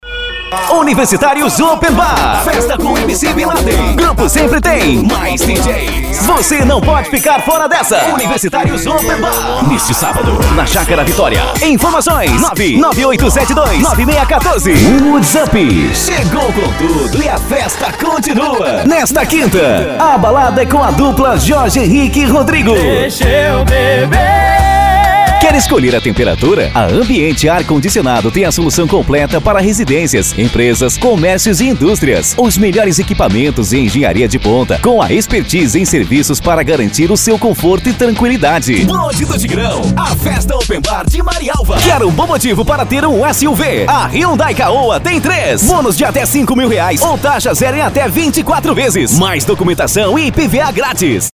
Spot Comercial
Vinhetas
Estilo(s):
Impacto
Animada